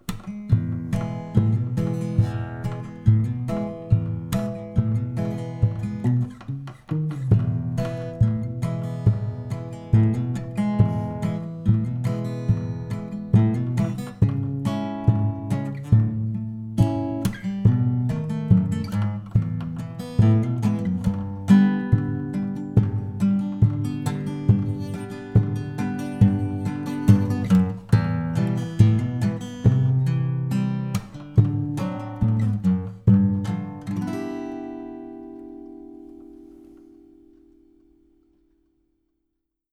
Esercizio di Chitarra Country / Blues
BASE-Esercizio-Country.wav